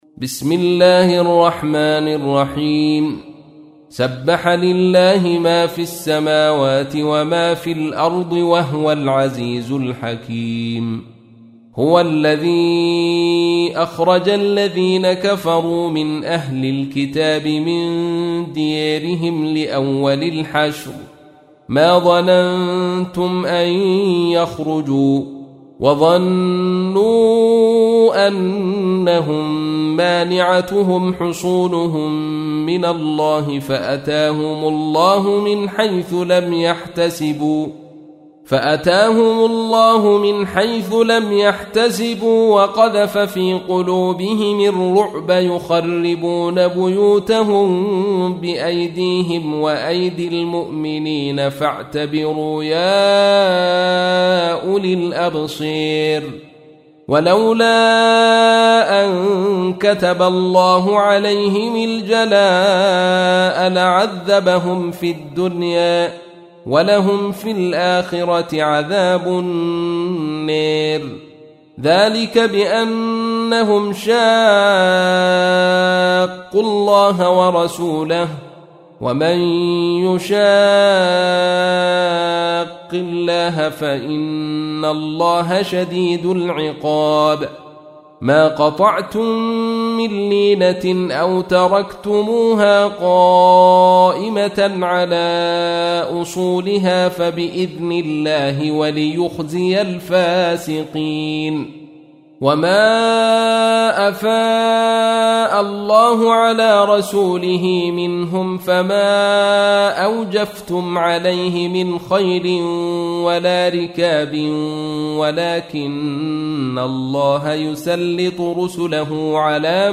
تحميل : 59. سورة الحشر / القارئ عبد الرشيد صوفي / القرآن الكريم / موقع يا حسين